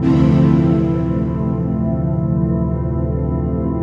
cch_synth_factory_125_Dm.wav